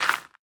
composter
fill_success3.ogg